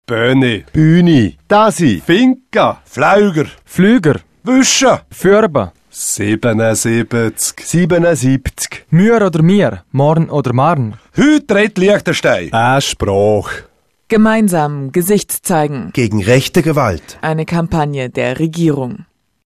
Radiospot Fans